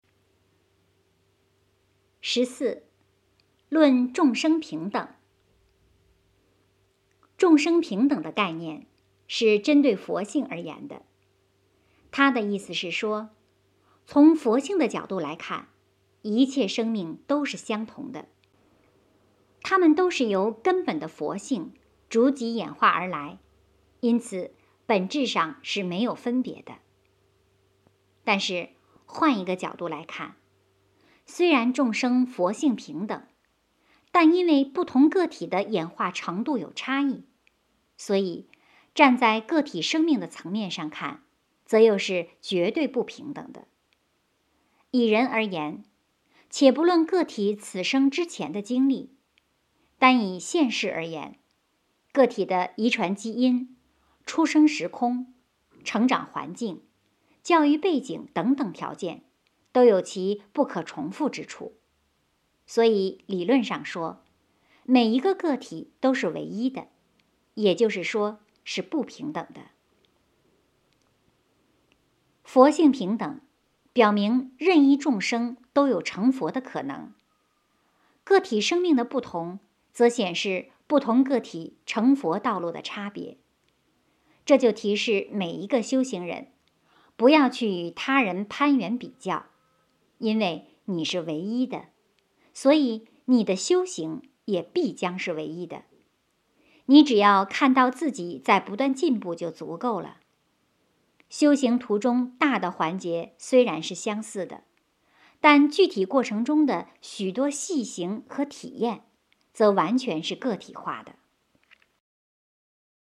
有声读物 - 实修世界